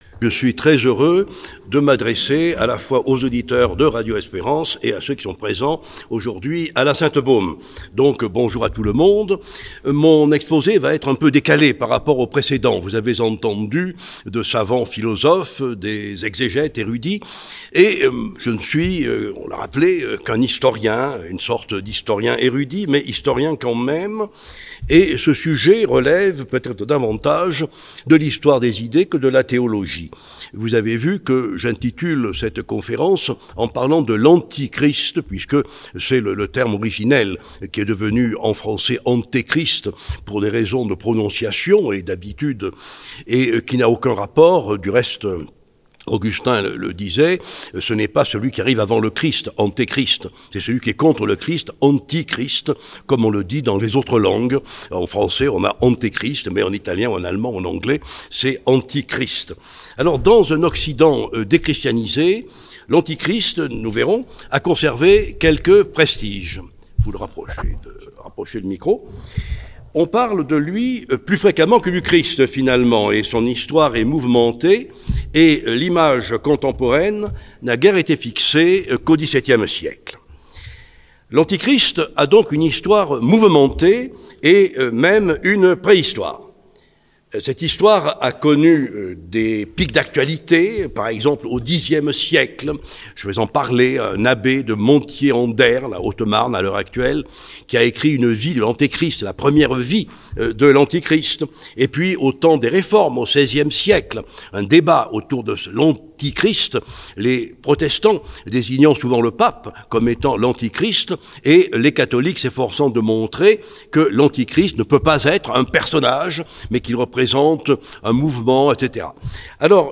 Ste Baume - Université d'été 2023 Père Jean-Robert Armogathe
Conférence de la semaine